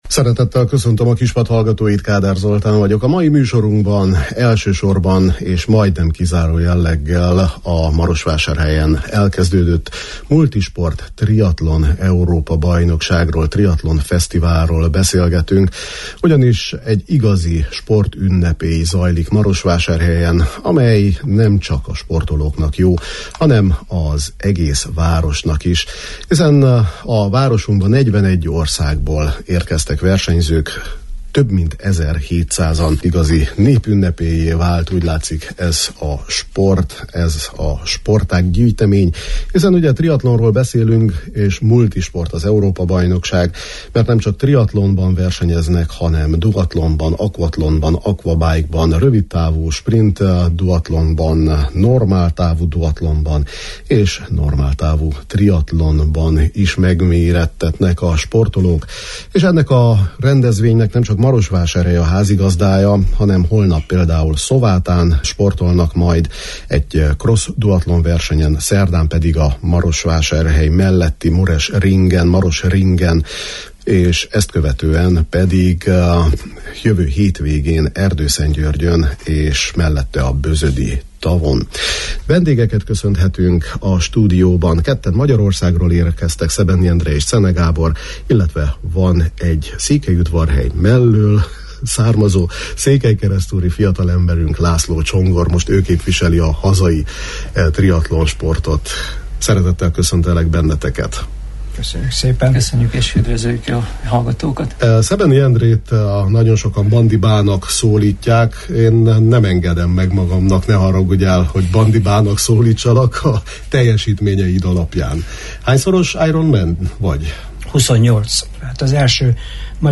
Para- illetve zongorahangoló triatlonos vendégeink voltak